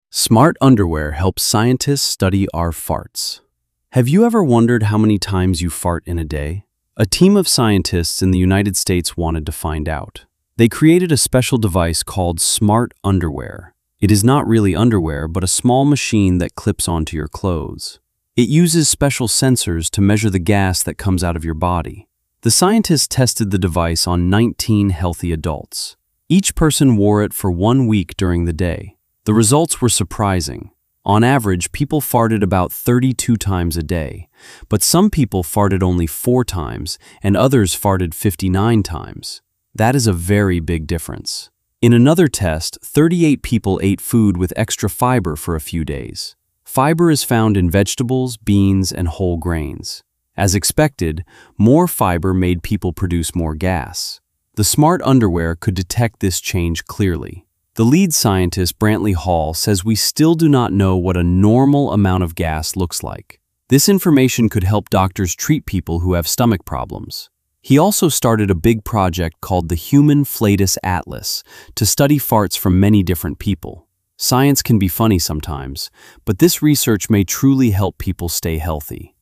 🔊 音読用音声